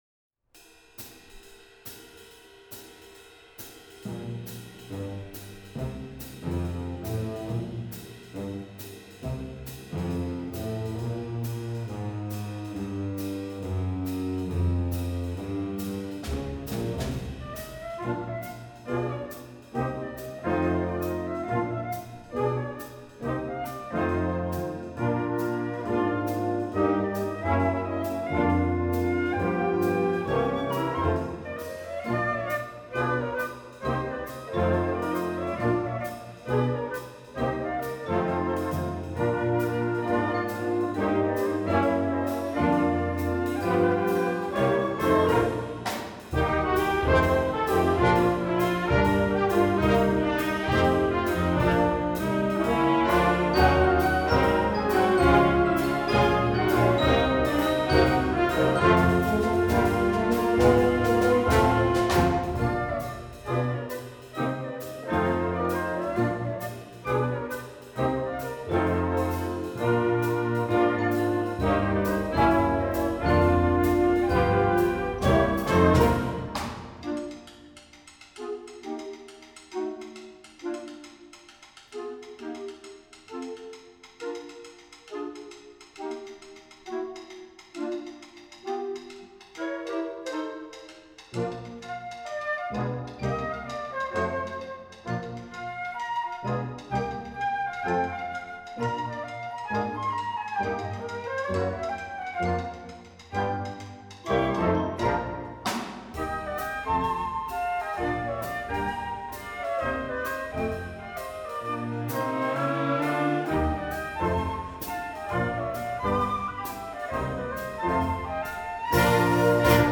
Voicing: Flute Section w/ Band